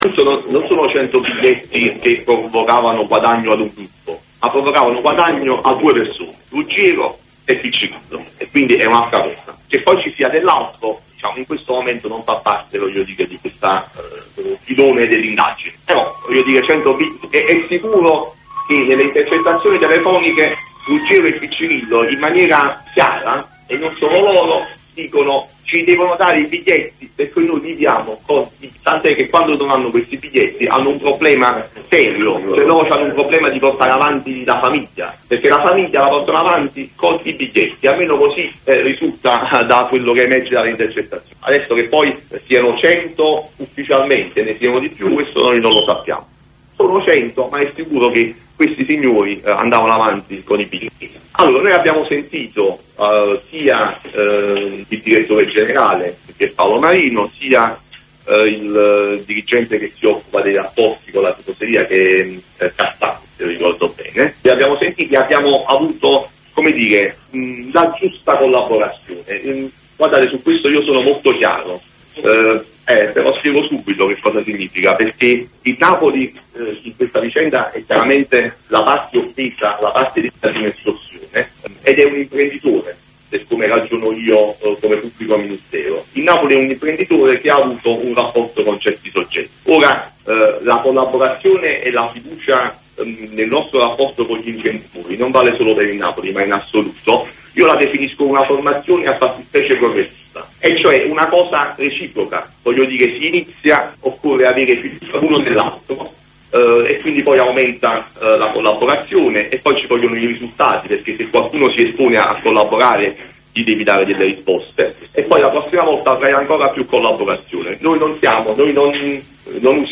Radio Kiss kiss nel pomeriggio ha trasmesso la conferenza stampa del sostituto procuratore Antonio Ardituro.